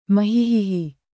Muhehehe (1) Efeito Sonoro: Soundboard Botão
muhehehe-1.mp3